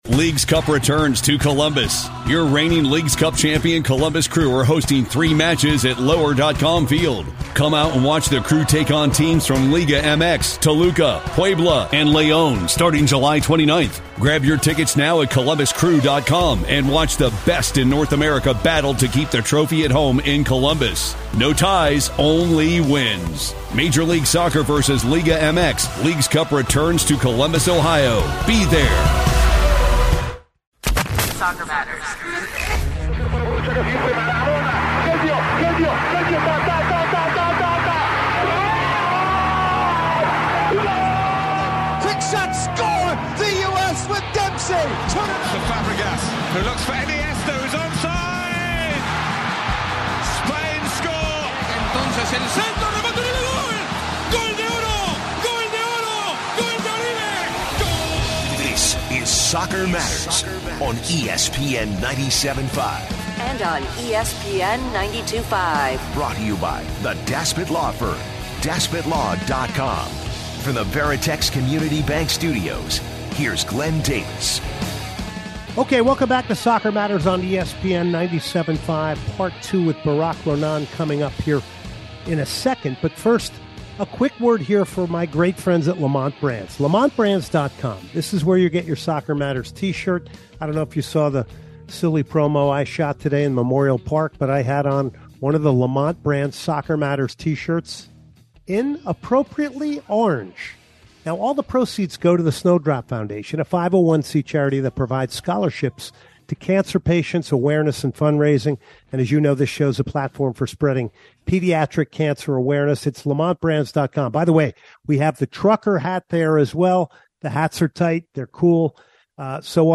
On this edition of "Best of SoccerMatters" we are joined by two interviews. 1.